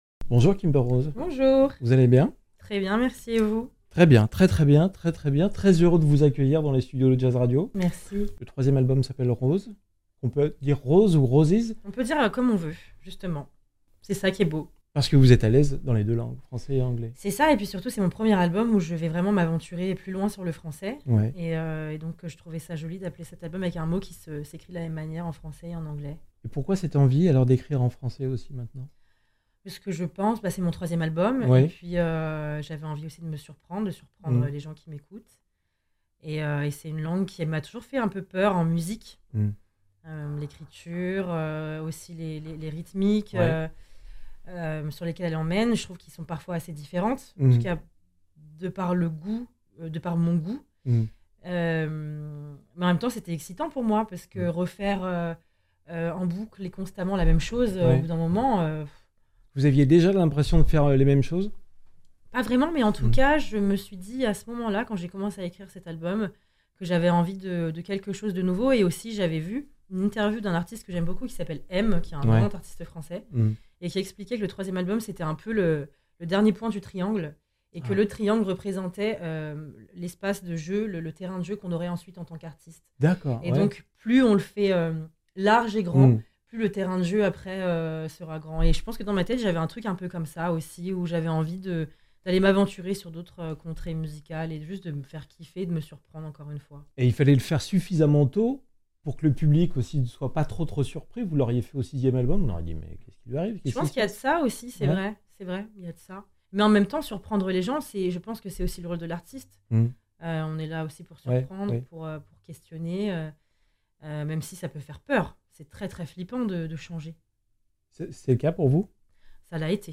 Interview Jazz Radio x Docks Live Sessions